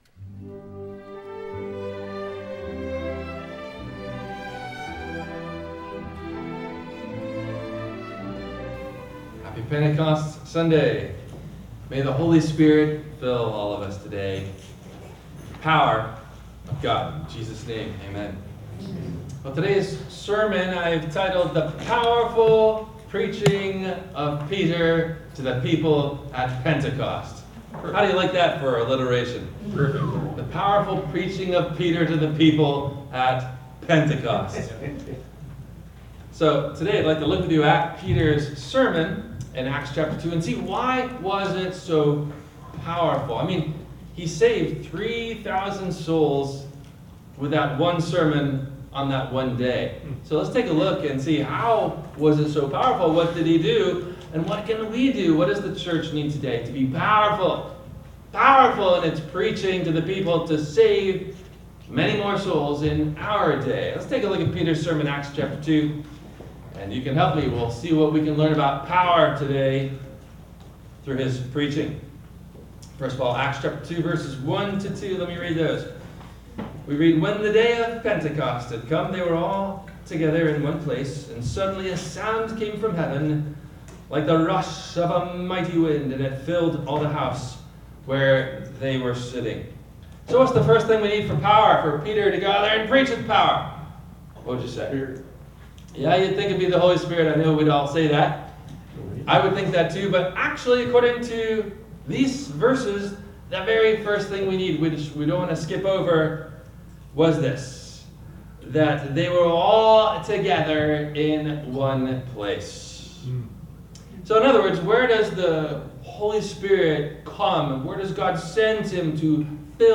Peters Powerful Preaching to the People at Pentecost – WMIE Radio Sermon – June 23 2025